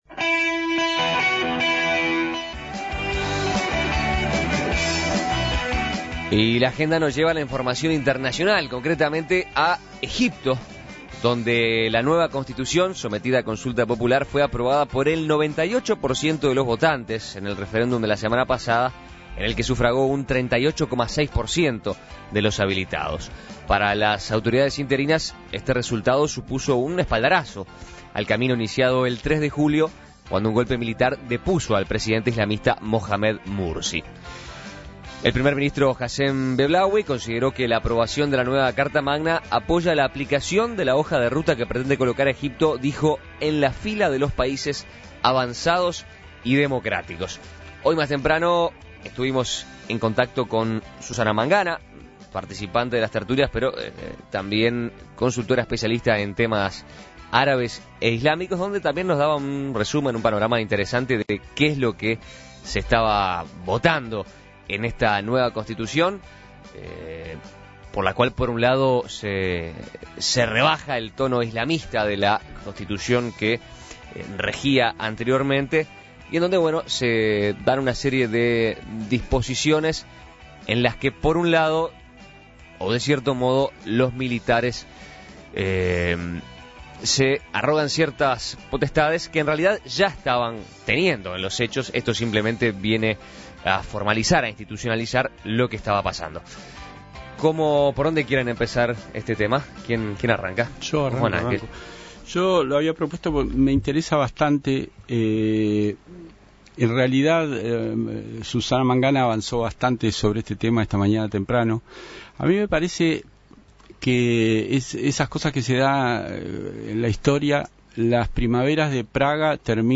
La tertulia